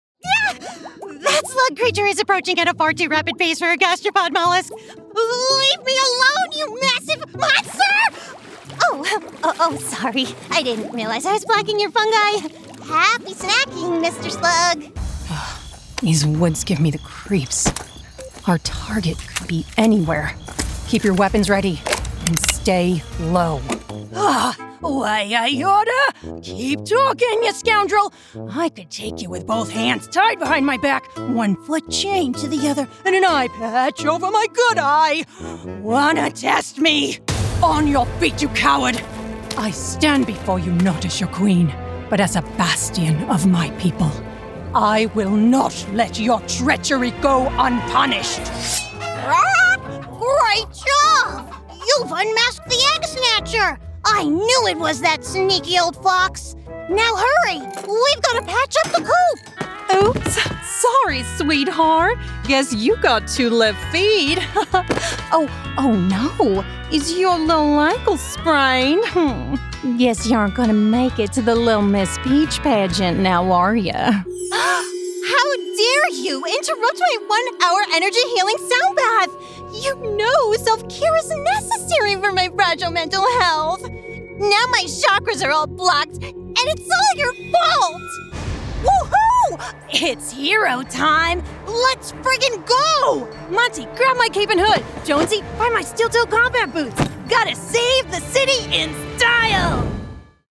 Demo
Child, Teenager, Young Adult, Adult, Mature Adult